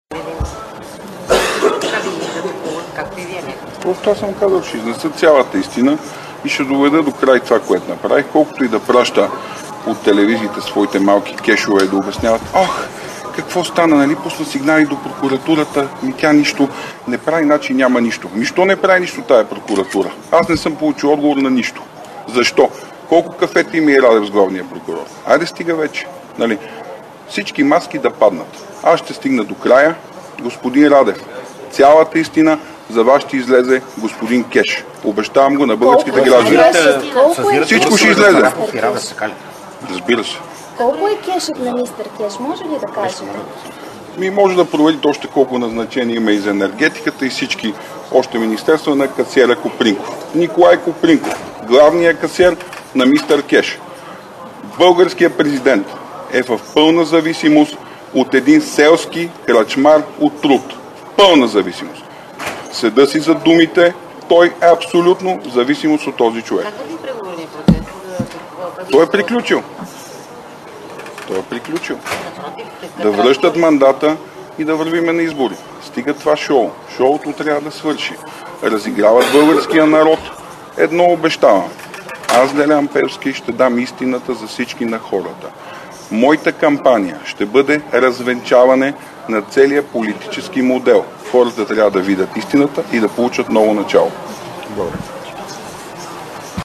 9.40 - Брифинг на председателя на ГЕРБ Бойко Борисов за Рая Назарян, разговорите с ИТН и Васил Терзиев.  - директно от мястото на събитието (Народното събрание)
Директно от мястото на събитието